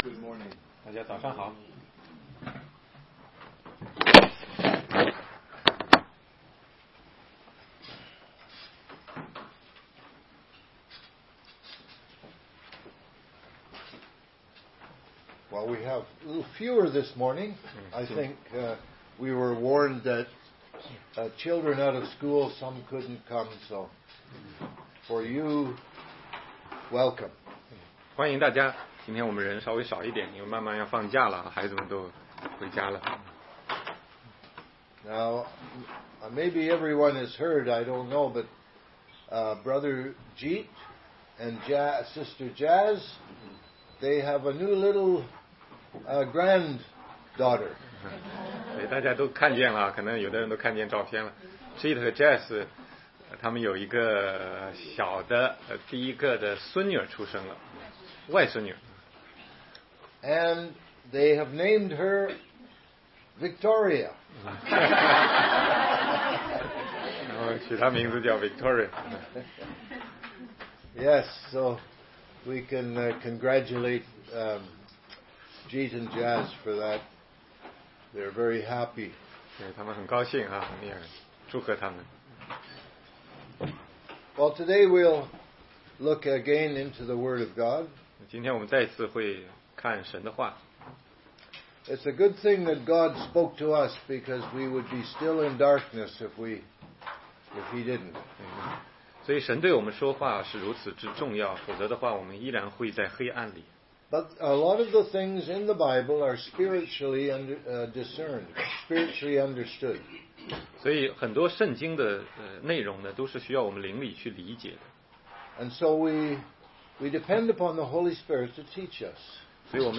16街讲道录音 - 怎样才能读懂圣经系列之十七